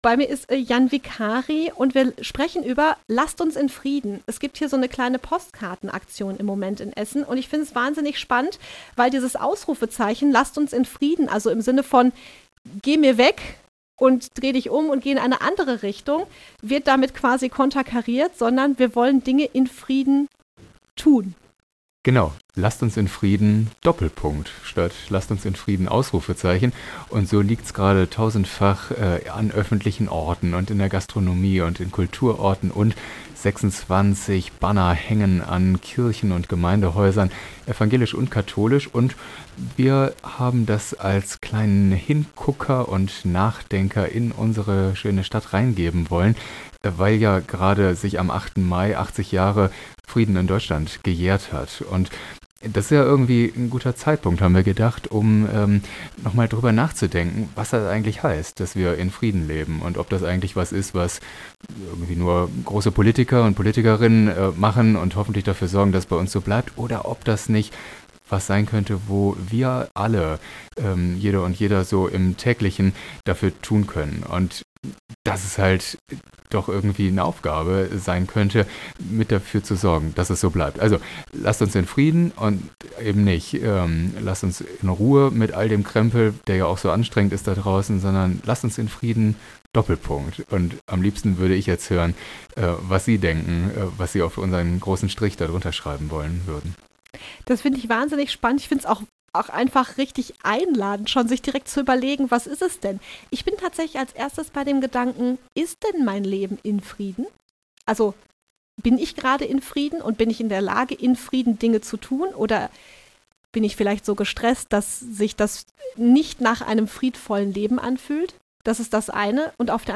Das erzählt er im Interview mit Radio Essen.